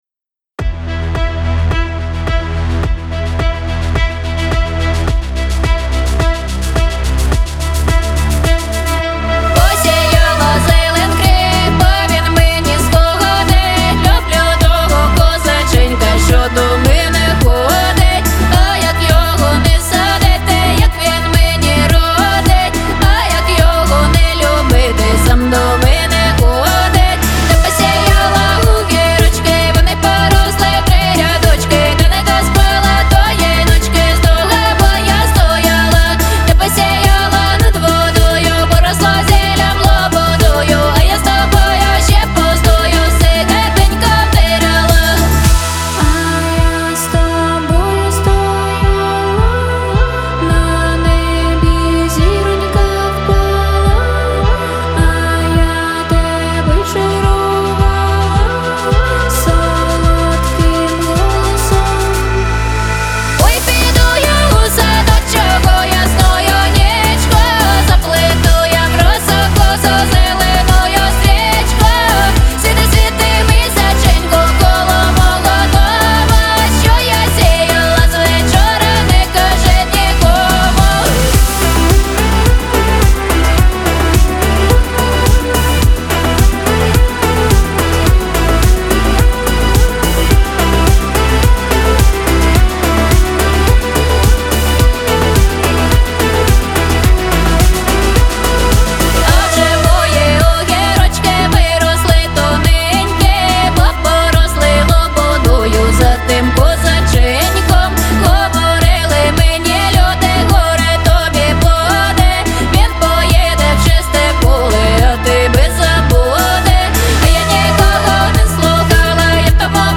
• Жанр: Electronic